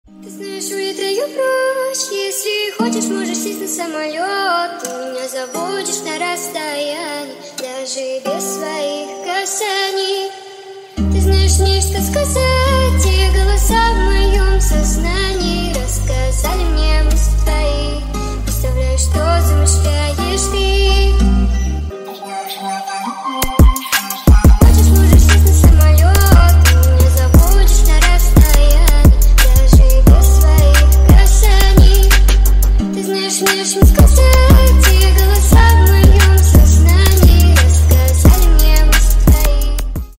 Громкие Рингтоны С Басами » # Кавер И Пародийные Рингтоны
Поп Рингтоны